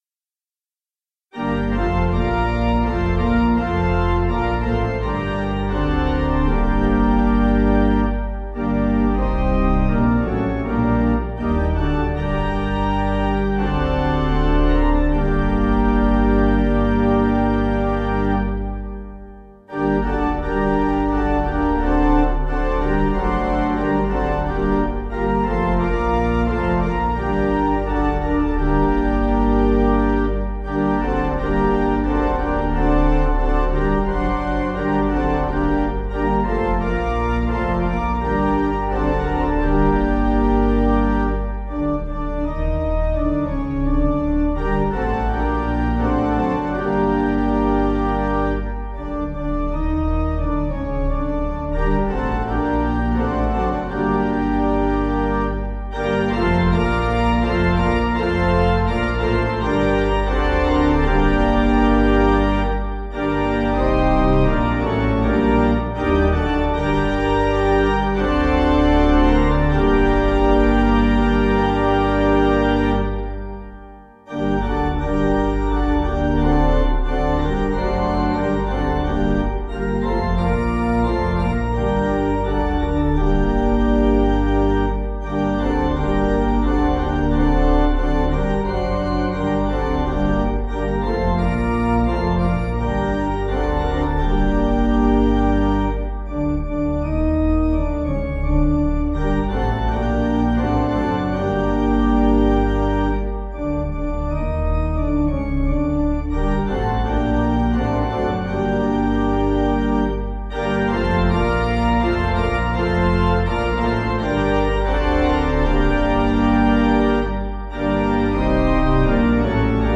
(CM)   3/Gm 497.7kb